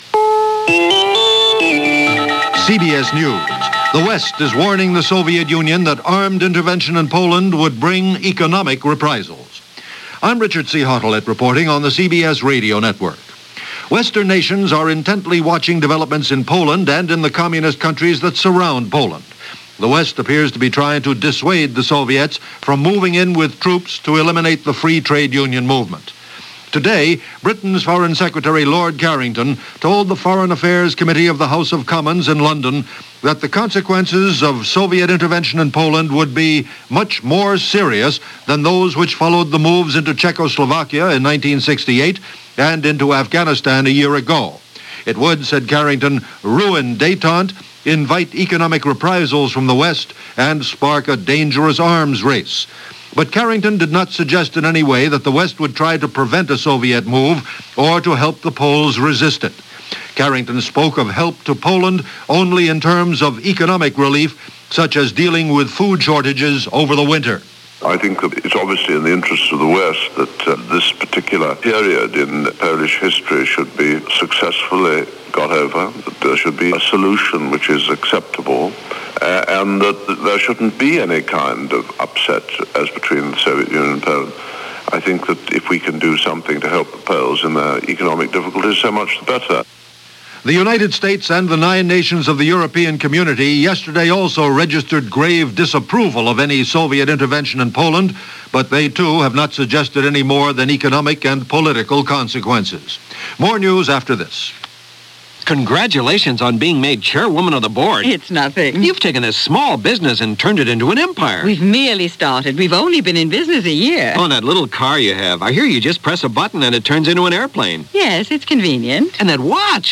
” description_text=”December 3, 1980 – CBS 9:00 am News
And that’s a little of what went on, this December 3, 1980 as reported by CBS Radio News.